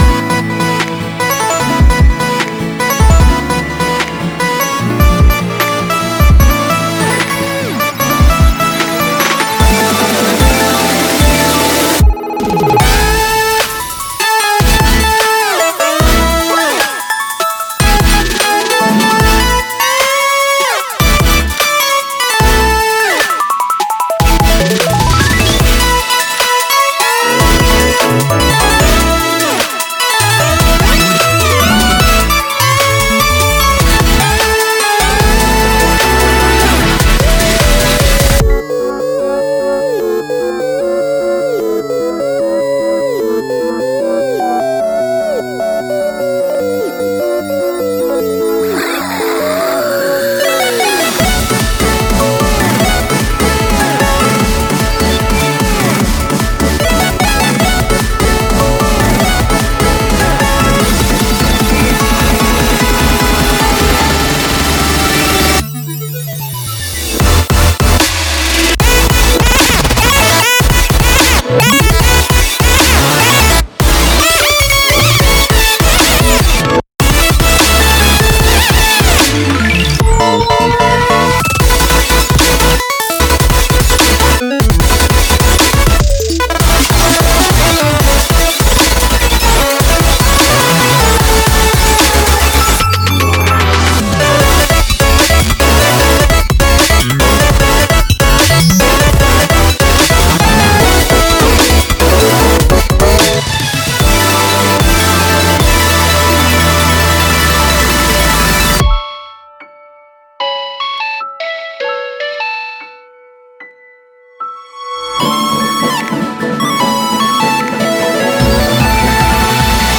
BPM150--1
Audio QualityPerfect (High Quality)